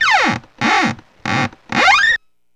Index of /90_sSampleCDs/E-MU Producer Series Vol. 3 – Hollywood Sound Effects/Water/WoodscrewSqueaks
WOOD SQUEA00.wav